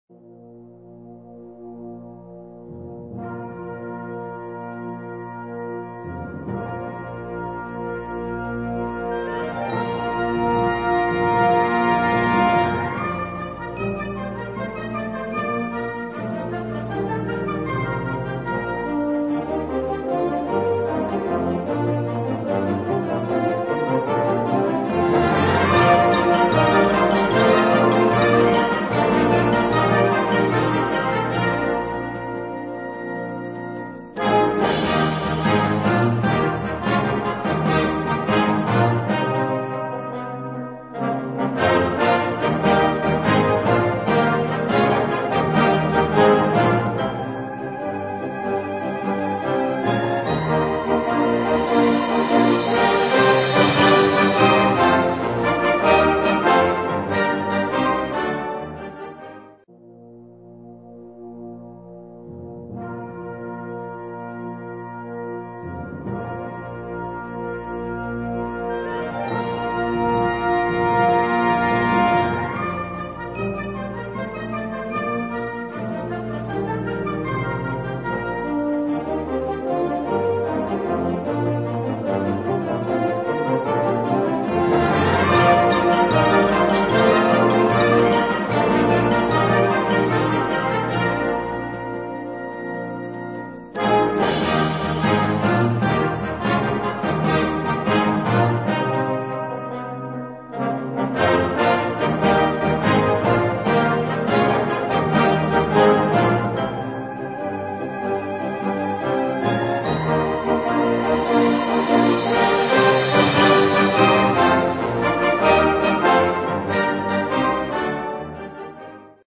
Kategorie Blasorchester/HaFaBra
Unterkategorie Suite
Besetzung Ha (Blasorchester)